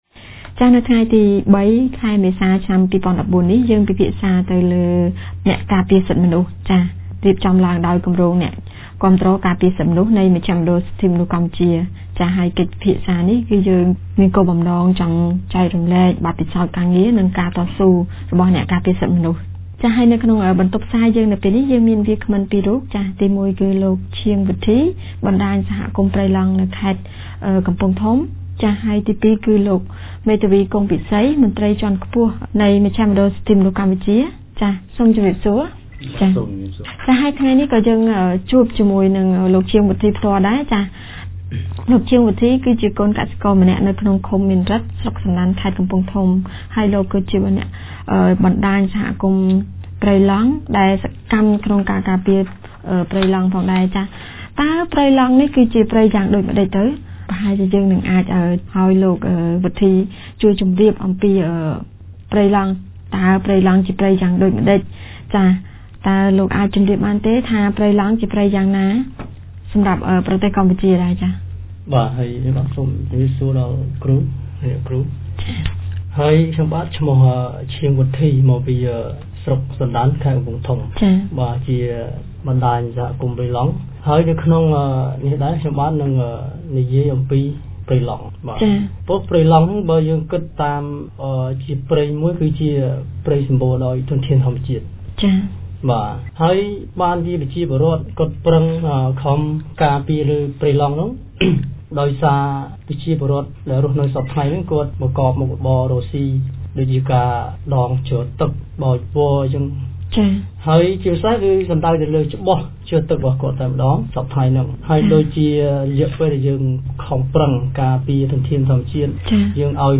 នៅថ្ងៃទី០៣ ខែមេសា ឆ្នាំ២០១៤ គម្រោងអ្នកការពារសិទ្ធិមនុស្ស បានរៀបចំកម្មវិធីពិភាក្សាផ្សាយផ្ទាល់លើប្រធានបទស្តីពី "អ្នកការពារសិទ្ធិមនុស្ស"។